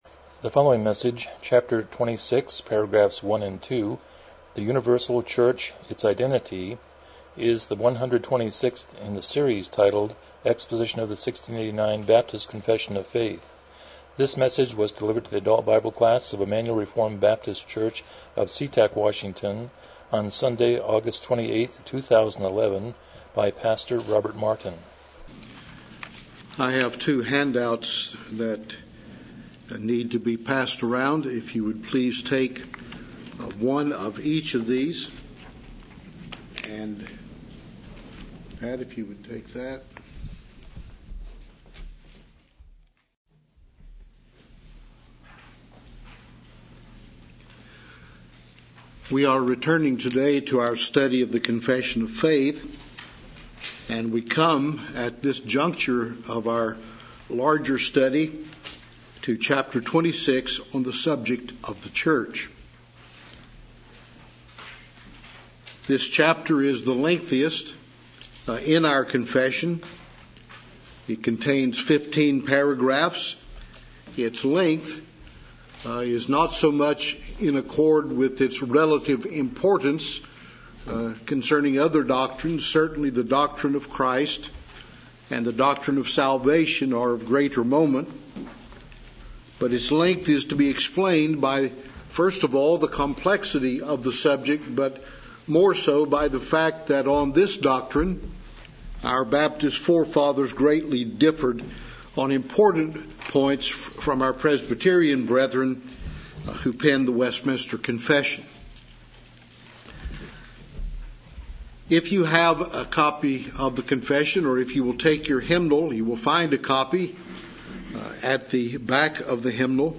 1689 Confession of Faith Service Type: Sunday School « 12 James 2:1-7 The Gospel According to Naomi